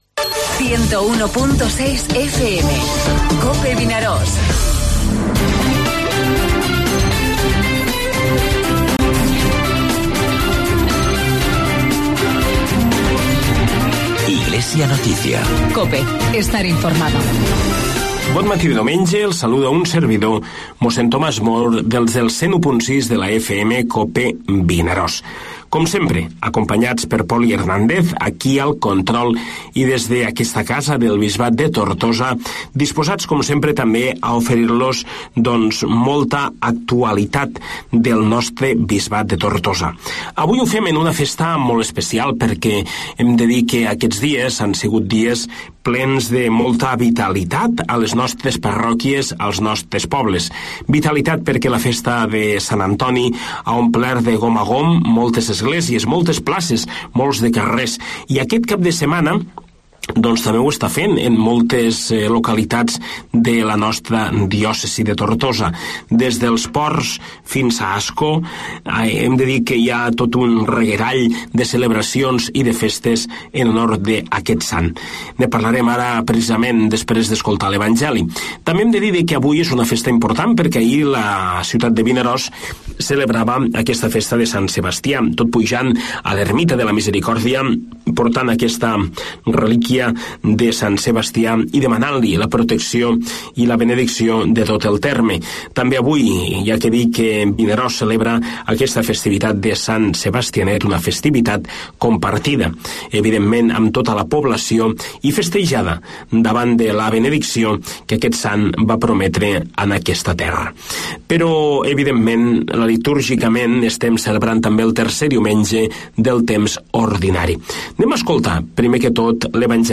Redacción digital Madrid - Publicado el 22 ene 2018, 15:13 - Actualizado 15 mar 2023, 04:48 1 min lectura Descargar Facebook Twitter Whatsapp Telegram Enviar por email Copiar enlace Espai informatiu del Bisbat de Tortosa, tots els diumenges de 9:45 a 10 hores.